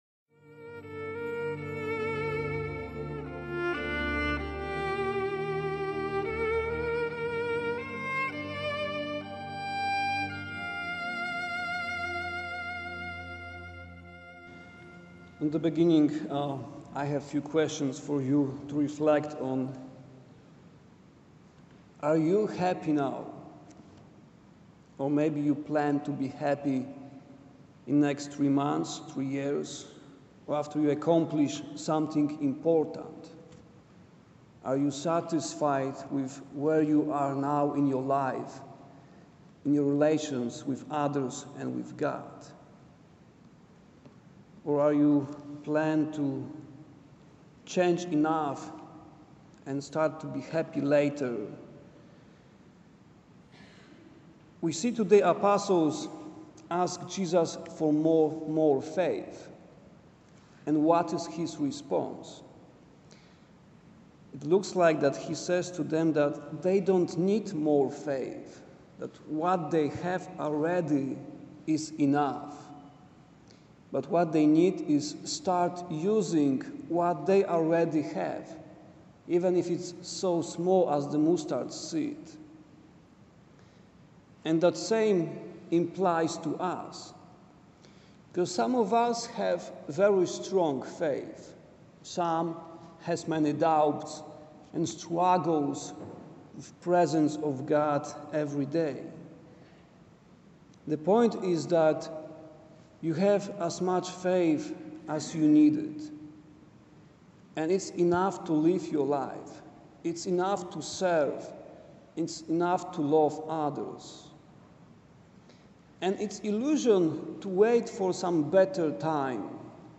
increase-our-faith-lord-homily-for-27-sunday.mp3